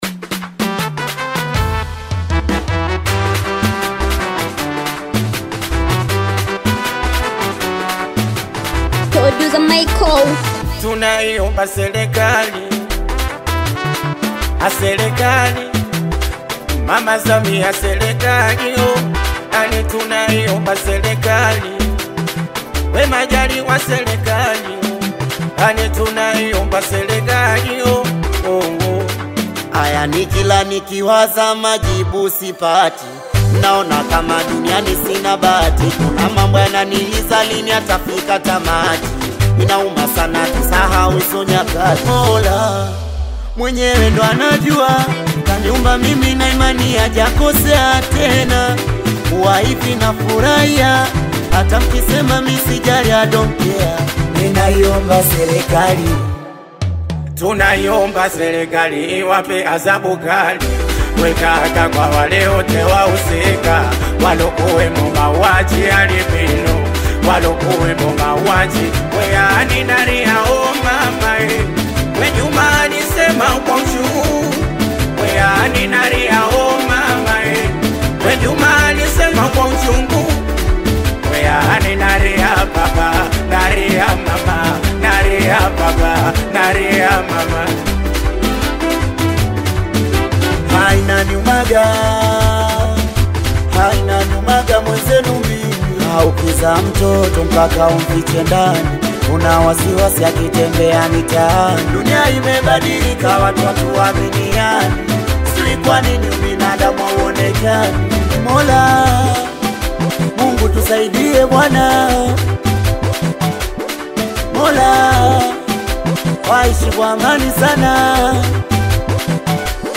Tanzanian artist